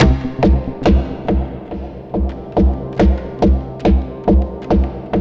SI2 PULSE0KL.wav